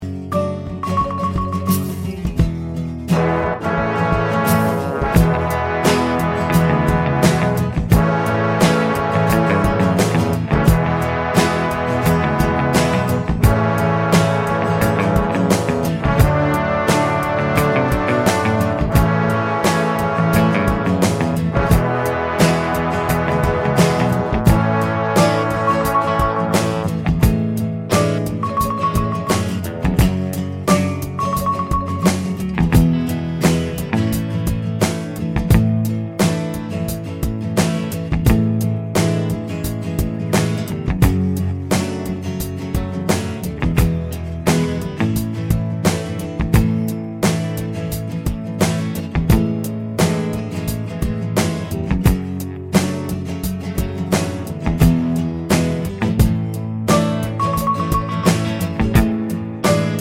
Professional Pop (1970s) Backing Tracks.